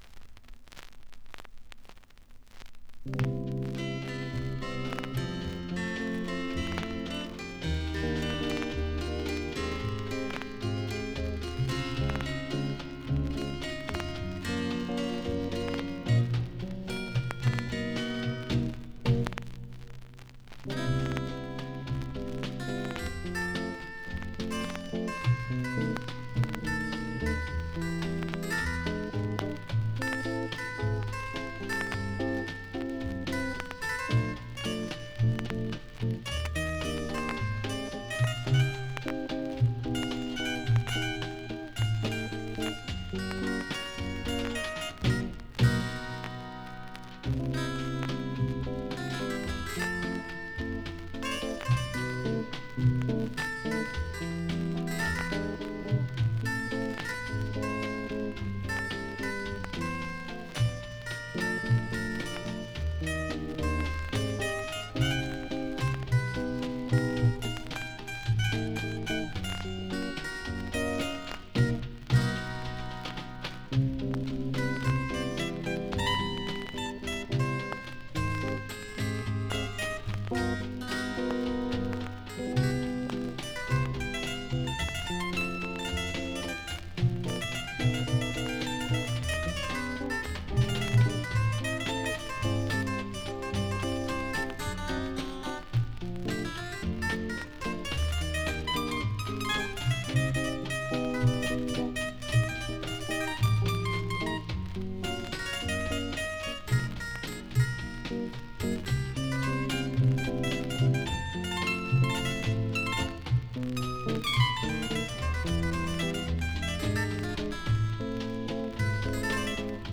Мозилла и Макстон крутят нормально с раритетными скрипами.